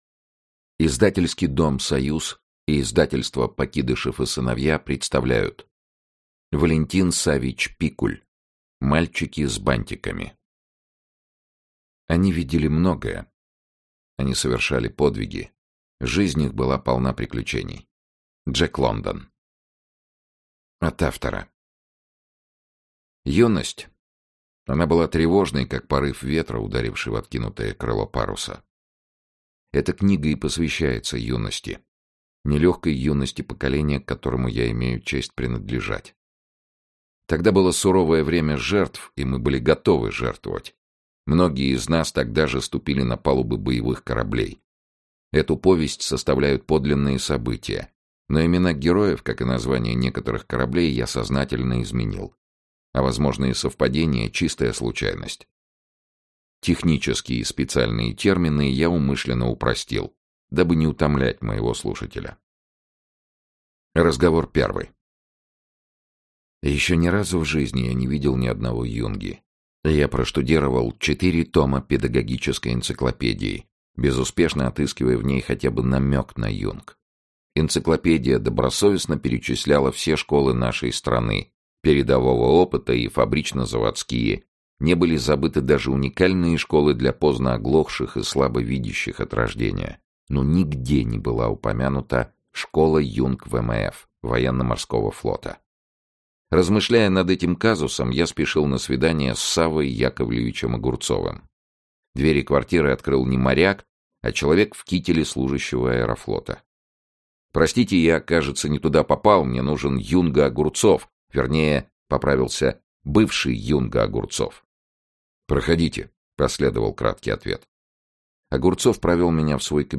Аудиокнига Мальчики с бантиками | Библиотека аудиокниг
Aудиокнига Мальчики с бантиками Автор Валентин Пикуль Читает аудиокнигу Сергей Чонишвили.